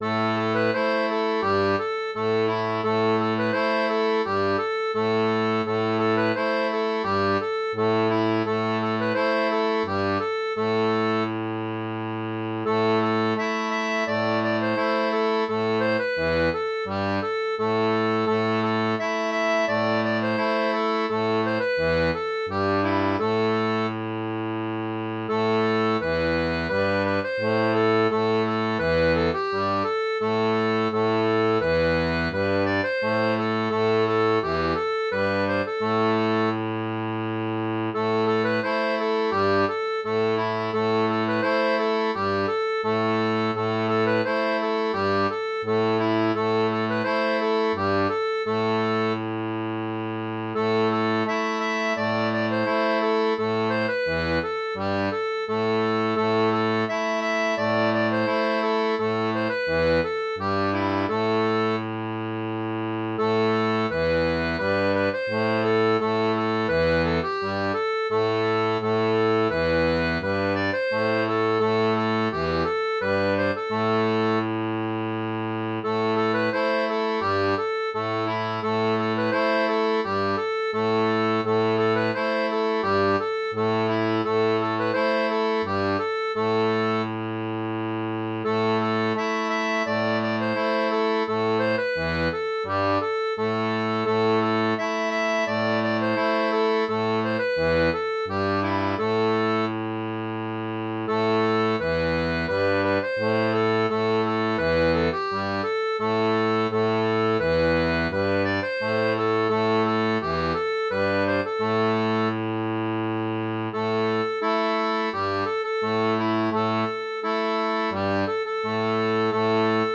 • une version transposée pour accordéon diatonique à 2 rangs
Gospel